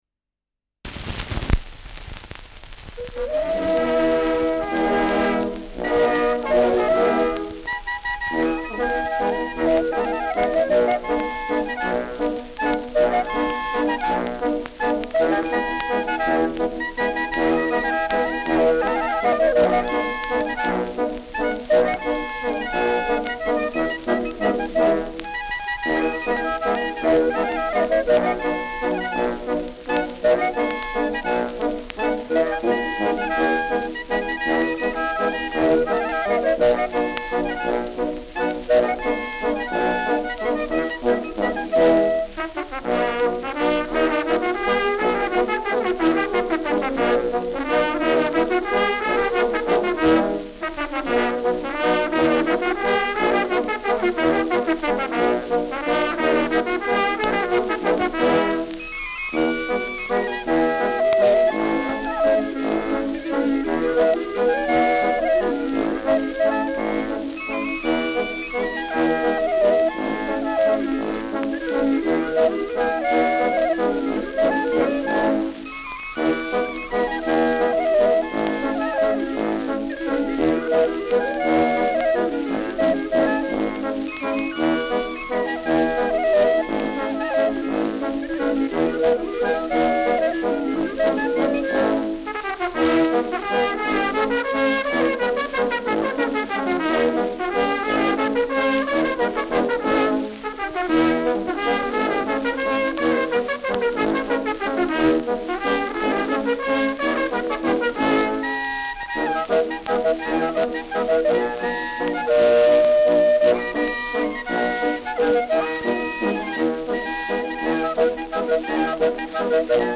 Schellackplatte
Stärkeres Grundrauschen : Durchgehend leichtes bis stärkeres Knacken
Kapelle Schmidt, Nürnberg (Interpretation)